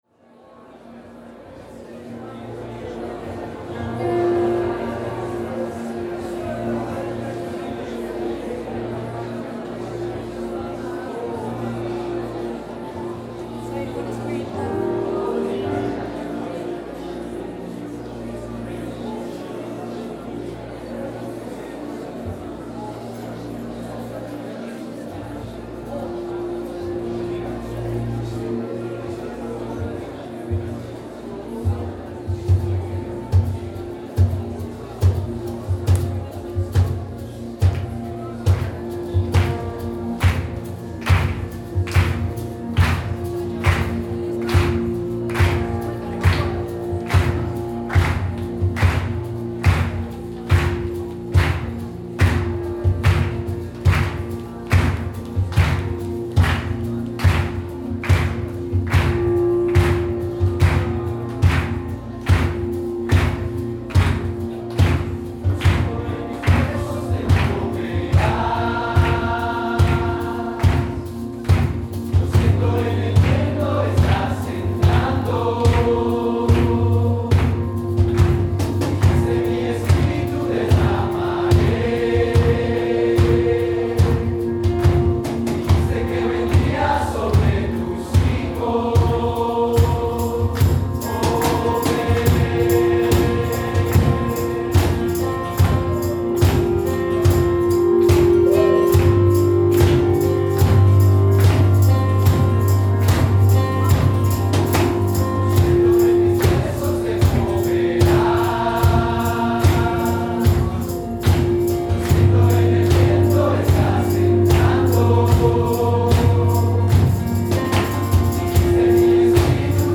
Nuestro-Anhelo-Coro-Sobredosis-2017-Fuego Fest.mp3